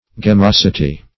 Search Result for " gemmosity" : The Collaborative International Dictionary of English v.0.48: Gemmosity \Gem*mos"i*ty\, n. [L. gemmosus set with jewels.